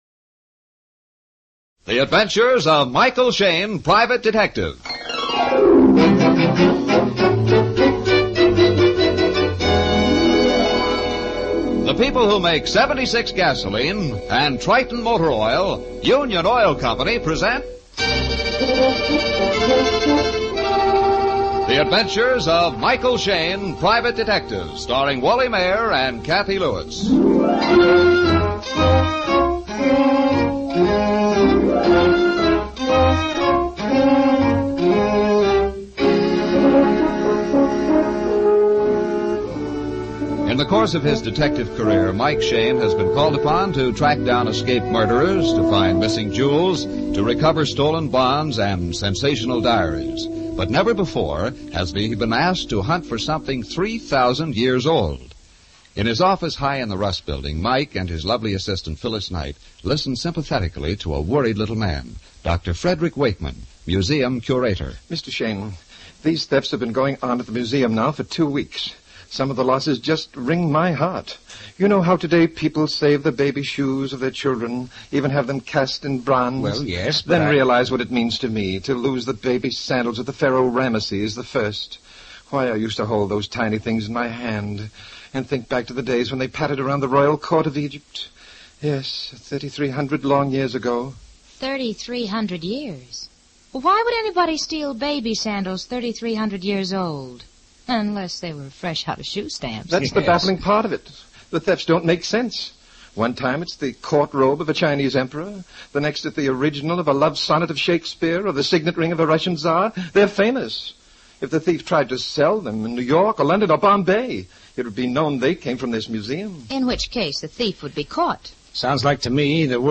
Michael Shayne 450730 Museum Thefts, Old Time Radio